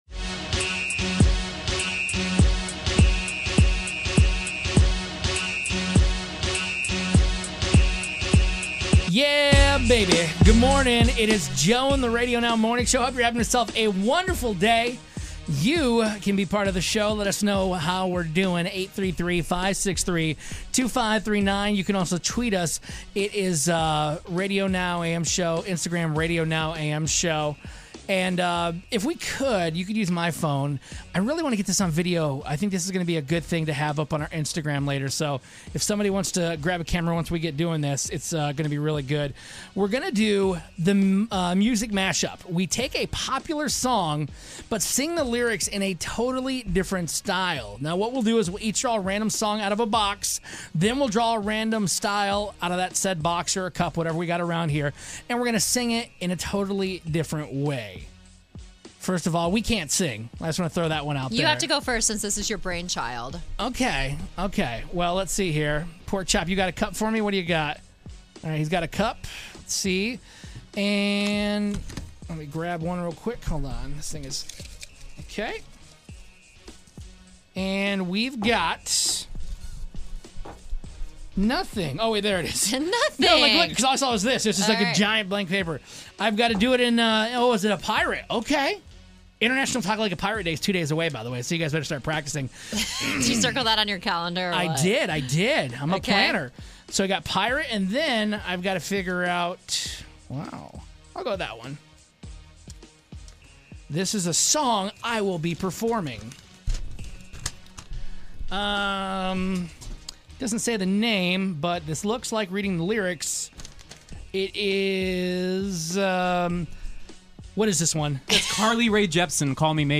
We randomly select a song we have to sing & a style we have to sing it in.